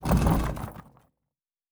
Stone 14.wav